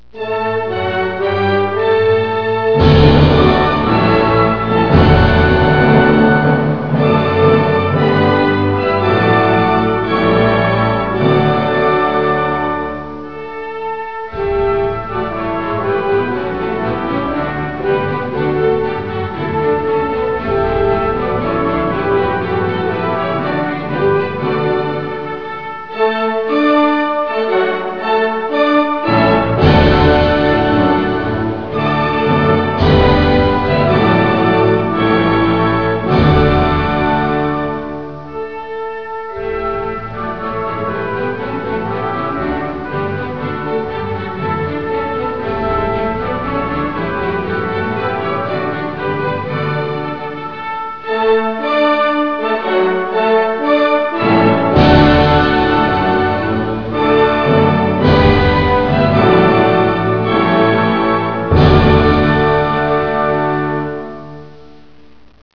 Fläche 338.145 km² Einwohner 5,181 Millionen Hauptstadt Helsinki Landessprache finnisch, schwedisch Währung Euro, Cent Nationalflagge Wappen Nationalhymne